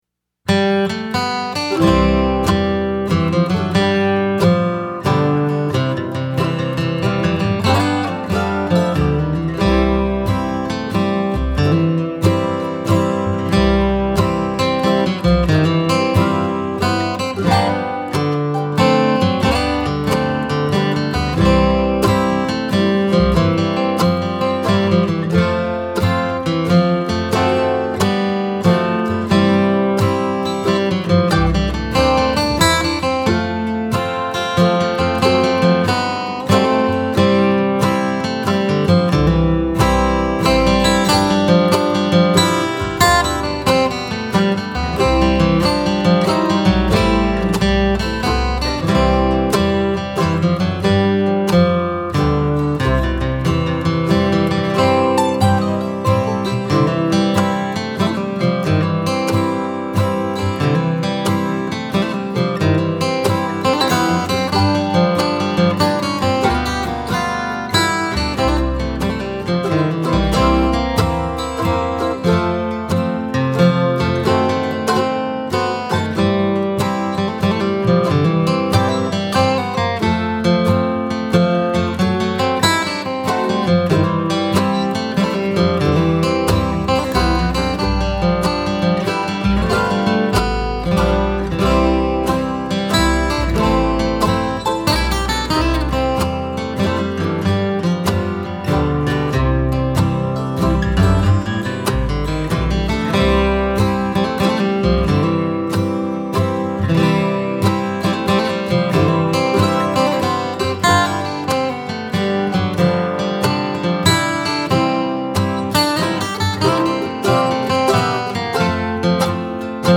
DIGITAL SHEET MUSIC - GUITAR SOLO
• Christmas, Bluegrass, Flatpicking, Guitar Solo